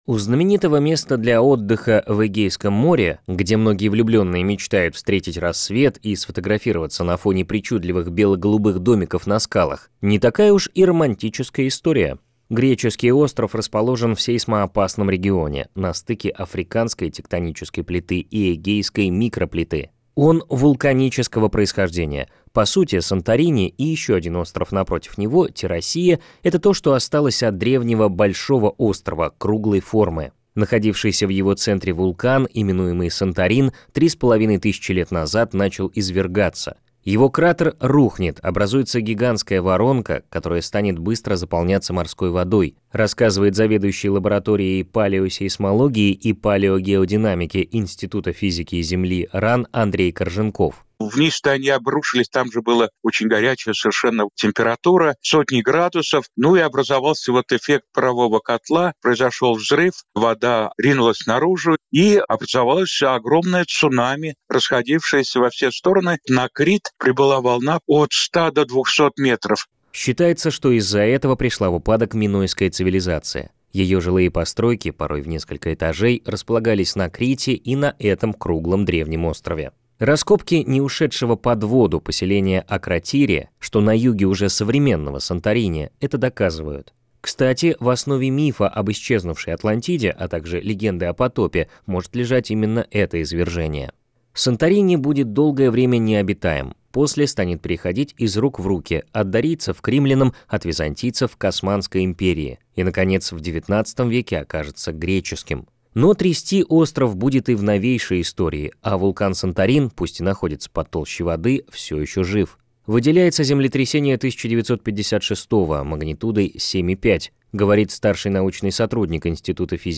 Ученые ИФЗ РАН рассказали о землетрясениях на острове Санторини в эфире радио «Звезда»
Запись эфира на радио «